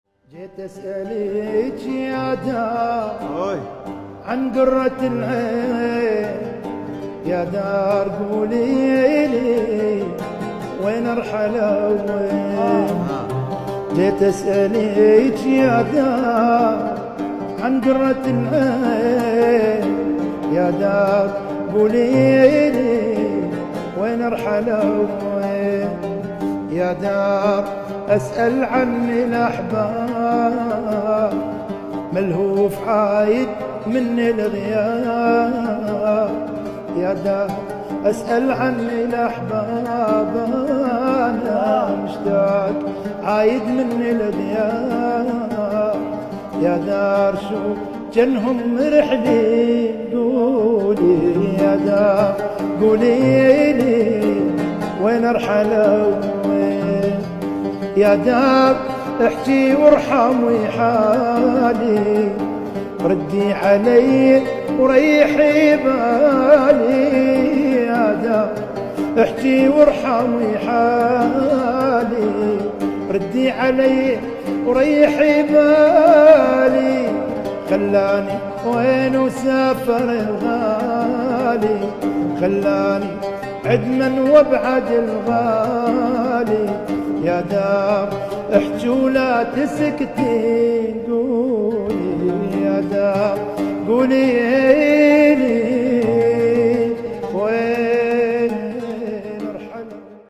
الأغاني التراثية